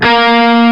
LES PAUL 10.wav